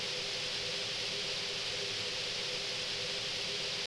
Index of /~quake2/baseq2/sound/cromavp2/ambients
steam2b.wav